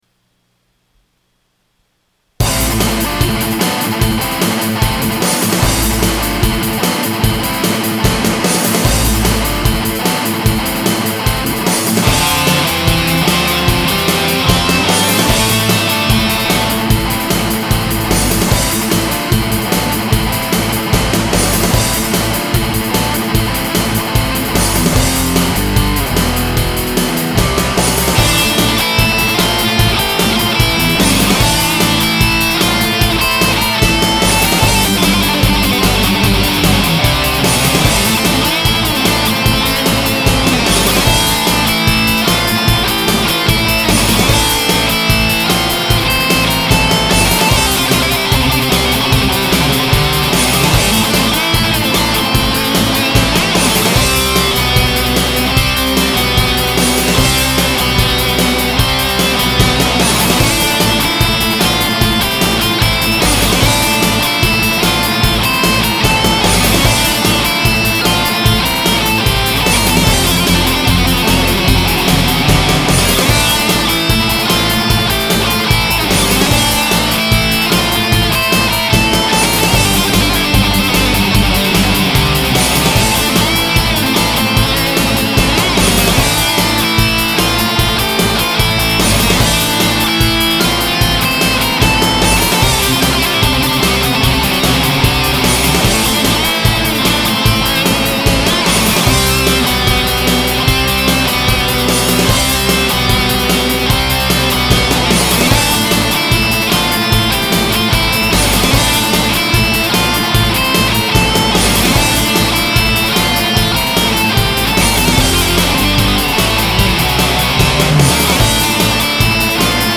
• Drums – Boss, Dr. Rhythm Drum Machine
• Recorded at the Park Springs Recording Studio